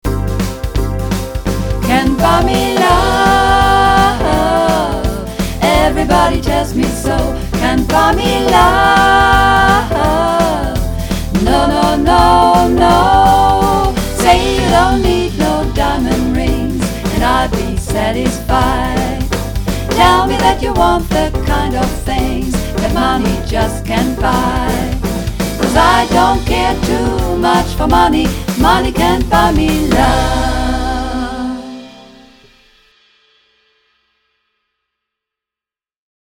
Übungsaufnahmen - Can't Buy Me Love
Runterladen (Mit rechter Maustaste anklicken, Menübefehl auswählen)   Can't Buy Me Love (Mehrstimmig)